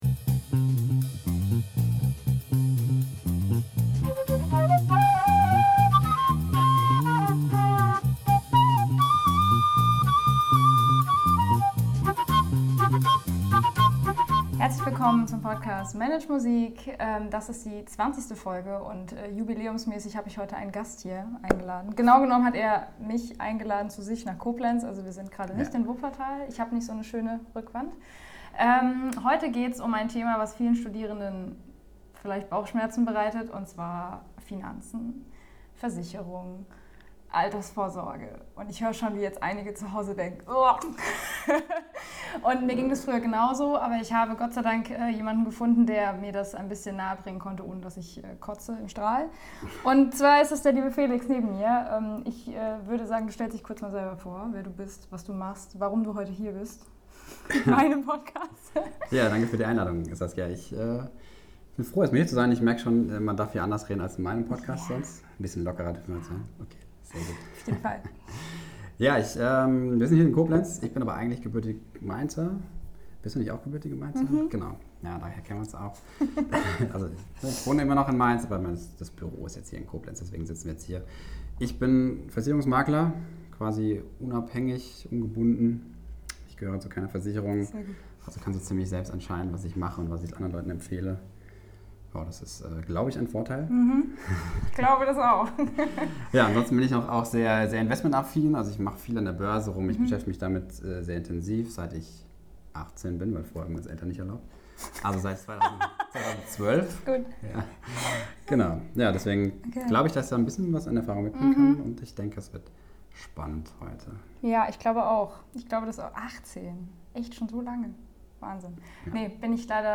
Eins kann ich euch versprechen: es wird nicht trocken, wie die meisten das Thema vielleicht empfinden (ging mir früher genauso), sondern wir haben ein interessantes, spannendes und informatives Gespräch mit ein paar Anekdoten aus unserem Leben gefüllt und dabei auch viel gelacht!